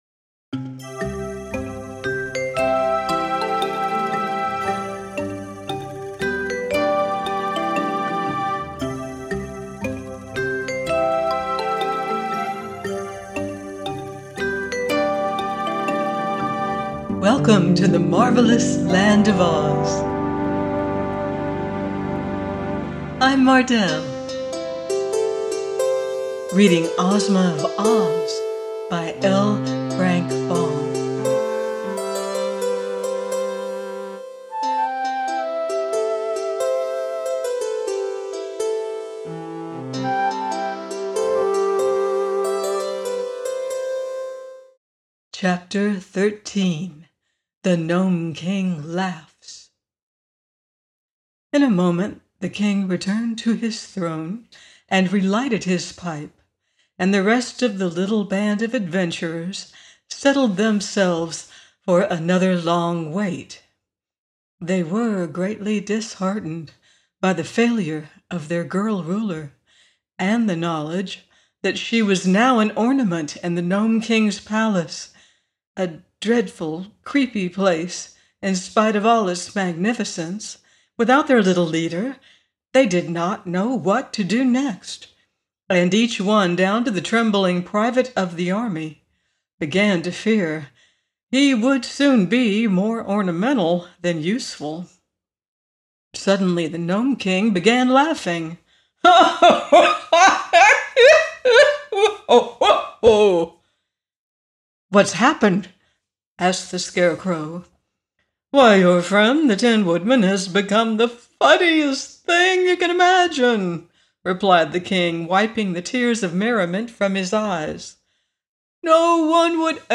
Ozma Of Oz – by L. Frank Baum - audiobook